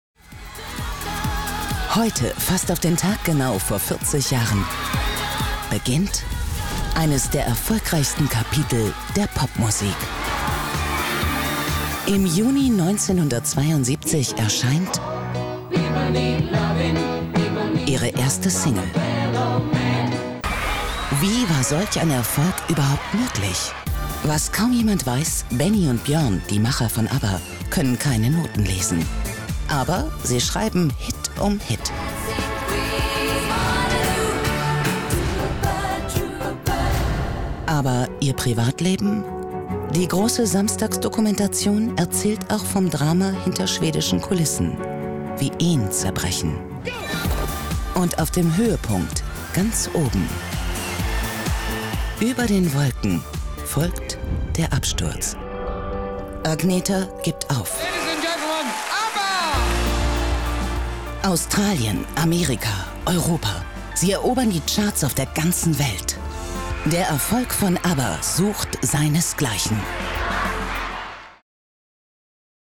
Warme, angenehme Stimme.
Strahlt viel Ruhe aus.
Sprechprobe: Industrie (Muttersprache):
female voice over artist